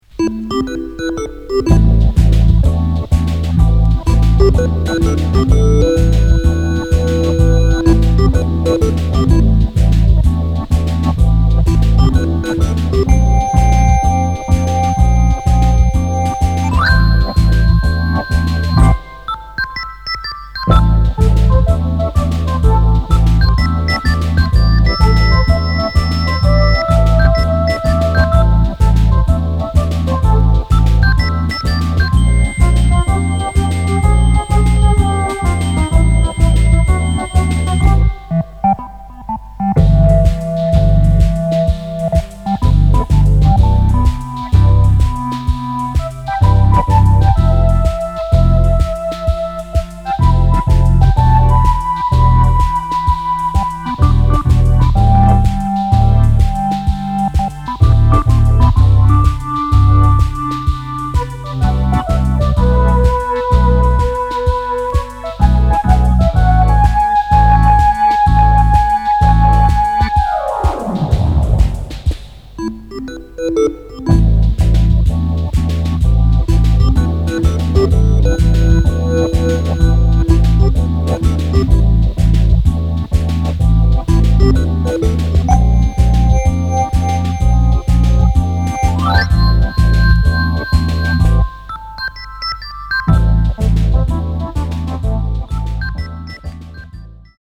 キーワード：電子音　ライブラリー　即興